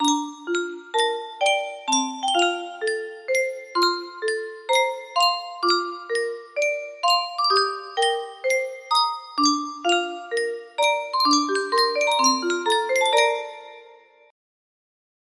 Yunsheng Music Box - God Bless America Y830 music box melody
Full range 60